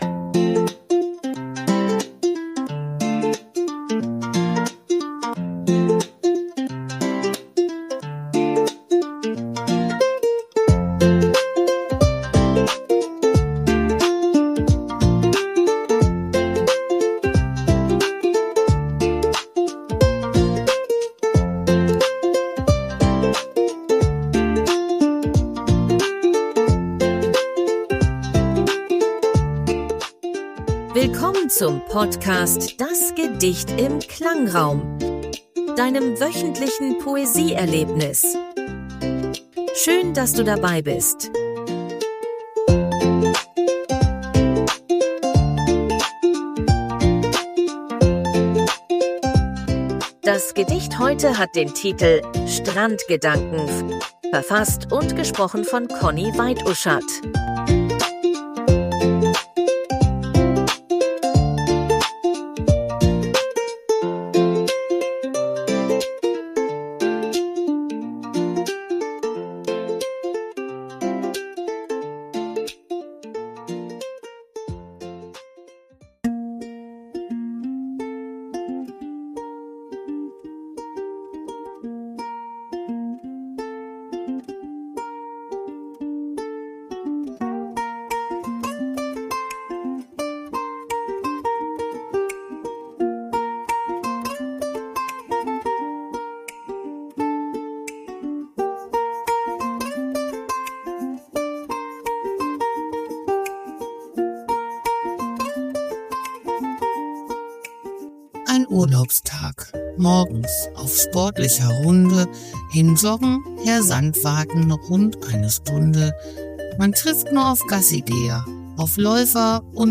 Die Hintergrundmusik wurde mit
KI-generiert.